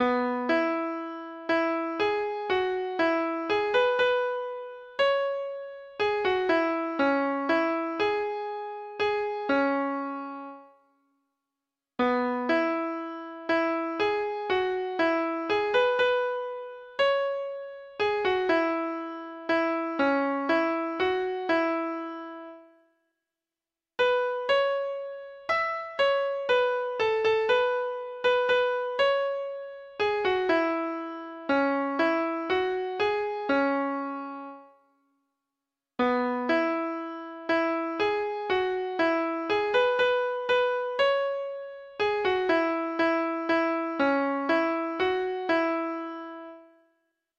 Free Sheet music for Treble Clef Instrument
Traditional Music of unknown author.